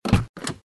car-door-opening.mp3